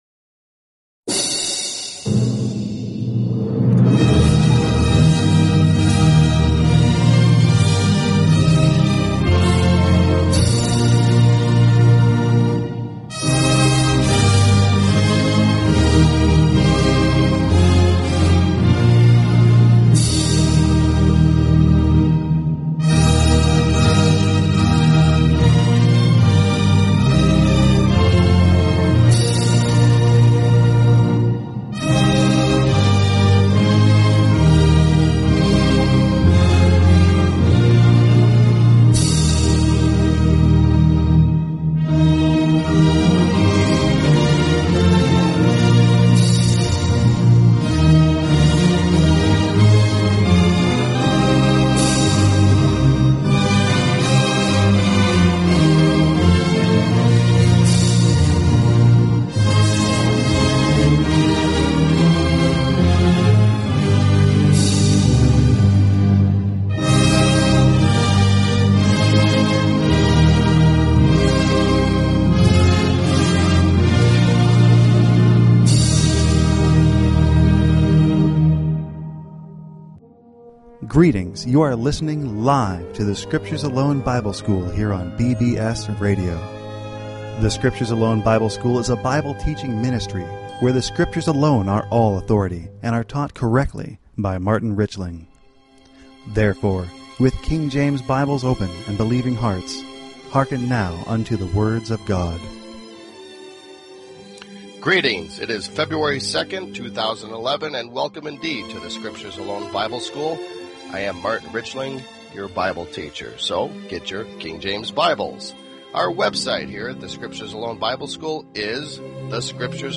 Talk Show Episode, Audio Podcast, The_Scriptures_Alone_Bible_School and Courtesy of BBS Radio on , show guests , about , categorized as